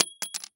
LockpickDrop_01.wav